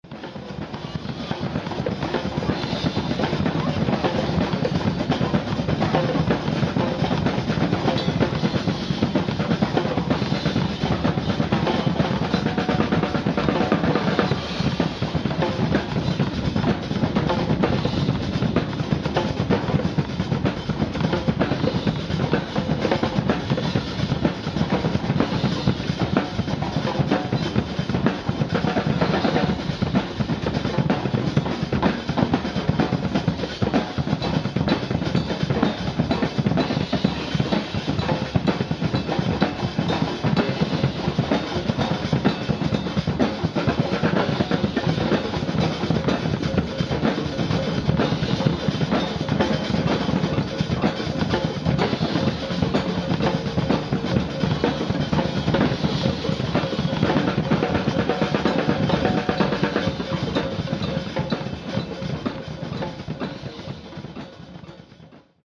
描述：泡泡，潺潺，反反复复
Tag: 水下 现场 记录